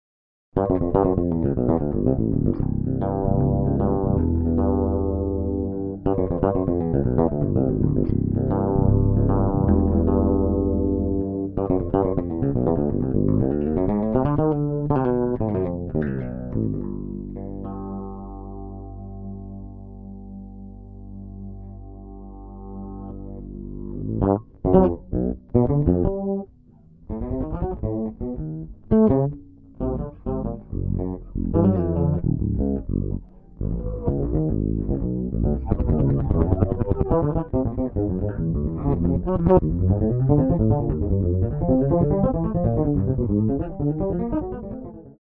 electric bass, keyboards & programming on track 6